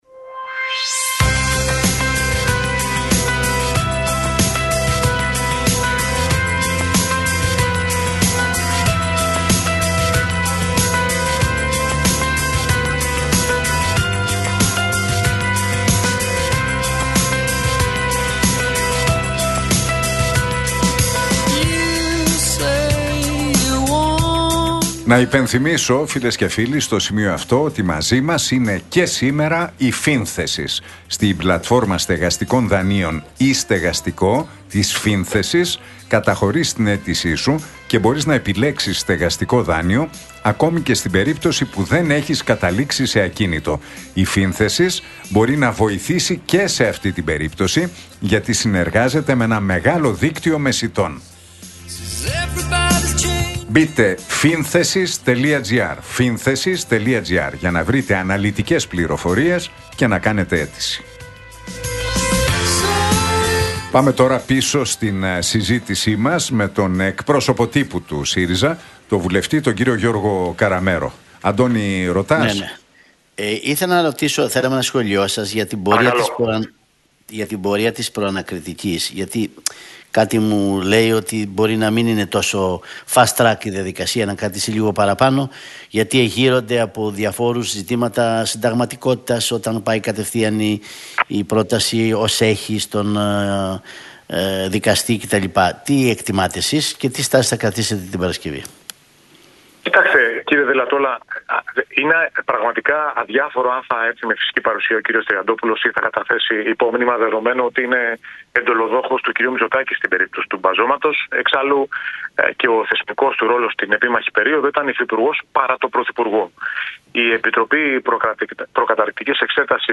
Ακούστε την εκπομπή του Νίκου Χατζηνικολάου στον ραδιοφωνικό σταθμό RealFm 97,8, την Τετάρτη 26 Μαρτίου 2025.